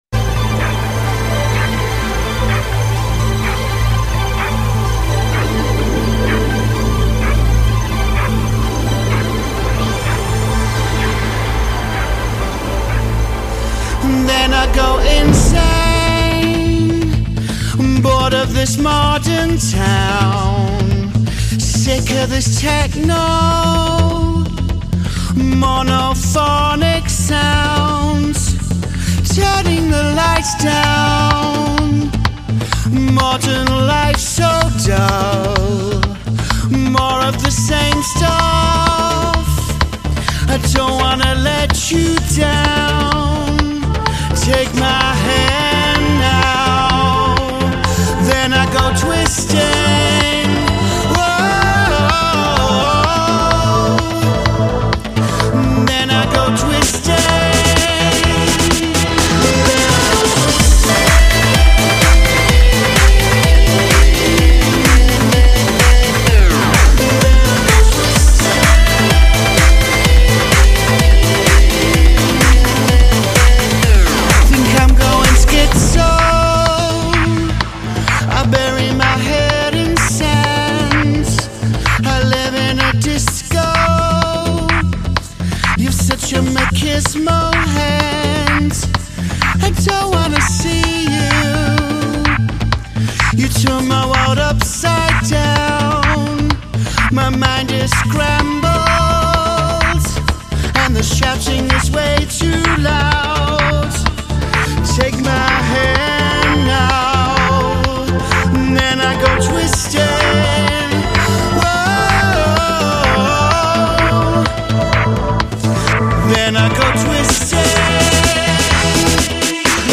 Music Review